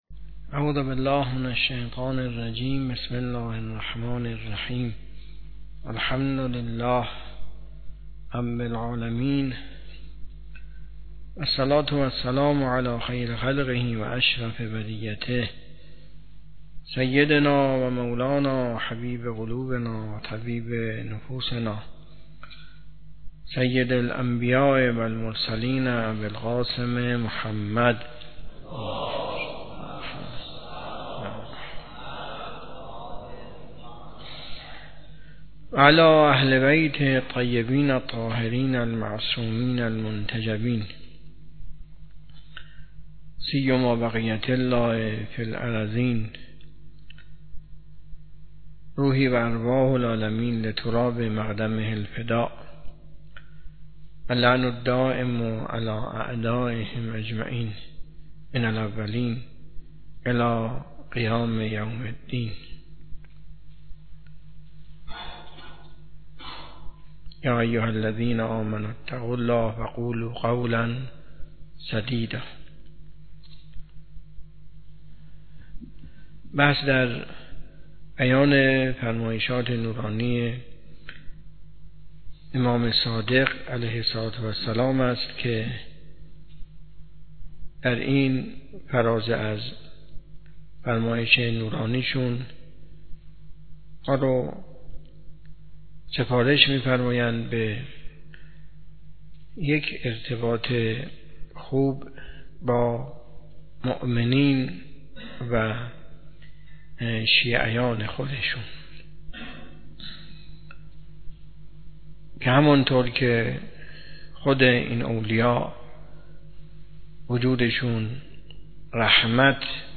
حوزه علمیه معیر تهران